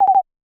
pda_alarm.ogg